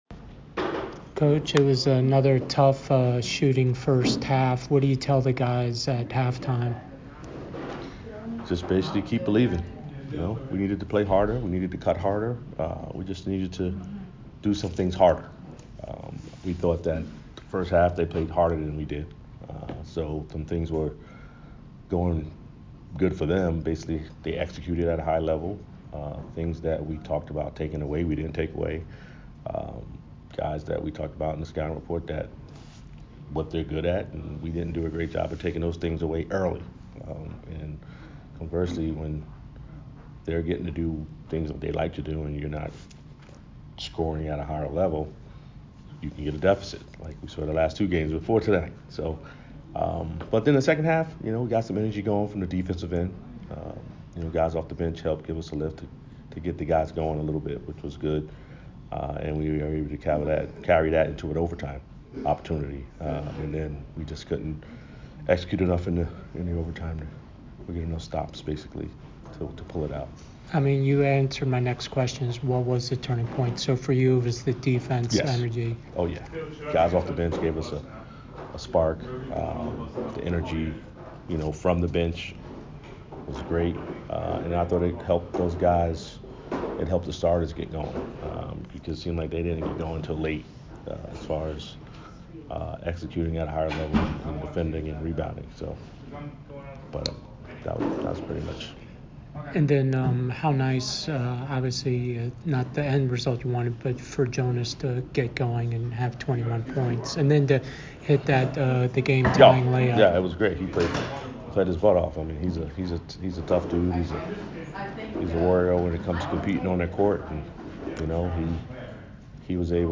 UC Davis Postgame Interview